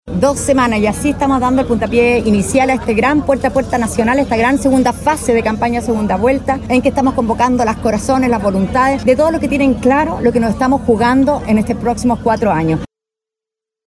A sus dichos se sumó la vocera del comando, la diputada Ana María Gazmuri (AH), quien sostuvo que en esta segunda fase de campaña, “se están jugando los próximos 4 años”.